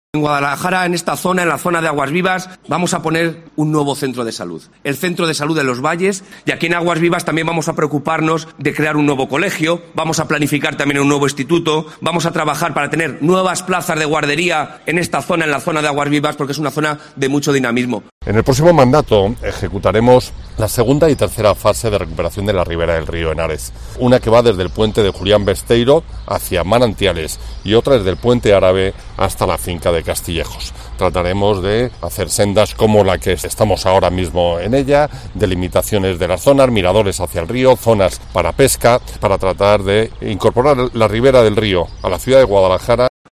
Anuncios electorales de Rojo y Román, candidatos del PSOE y del PP a la Alcaldía de Guadalajara